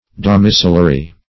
Domicillary \Dom`i*cil"l*a*ry\, a. [LL. domiciliarius.]